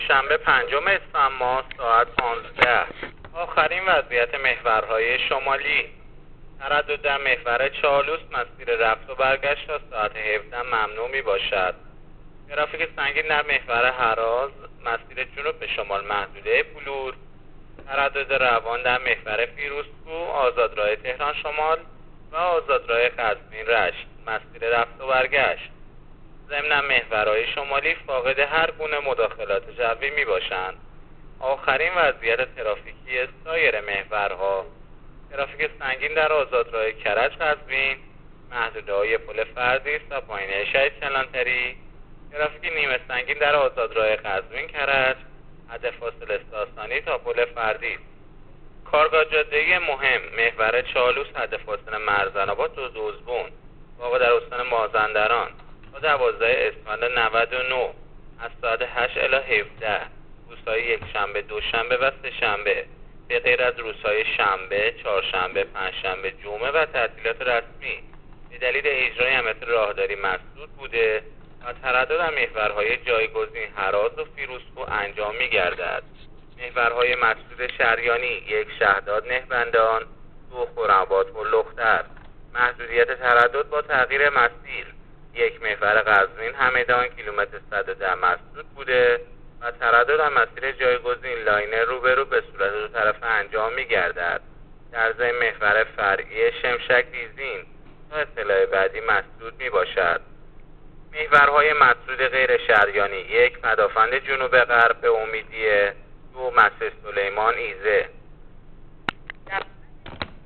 گزارش رادیو اینترنتی از آخرین وضعیت ترافیکی جاده‌ها تا ساعت ۱۵ پنجم اسفند